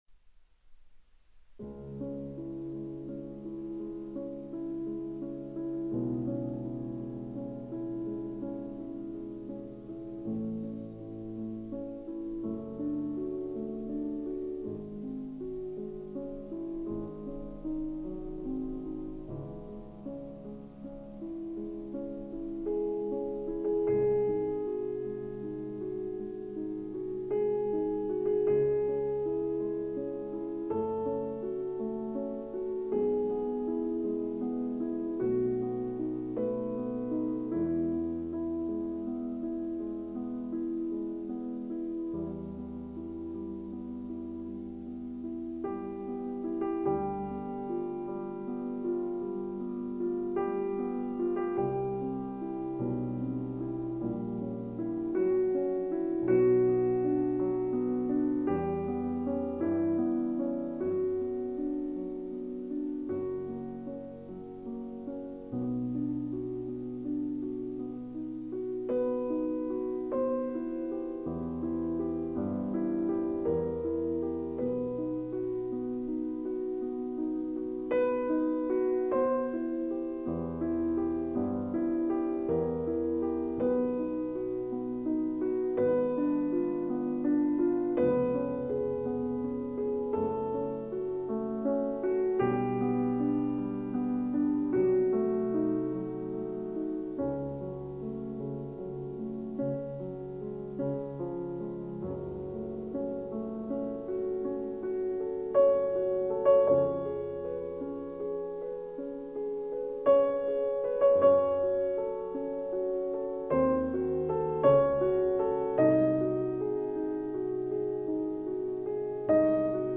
音樂類型：古典音樂
第一樂章為持續的慢板，二二拍，三段體。
雖然是二二拍子，但是左手均勻的三連音卻讓音樂聽來像三拍子，而且營造出迷人的幻想性。
第一樂章整體的表達屬於一種朦朧、低迷的調子，性格隱諱且不好處理。
I.Adagio sostenuto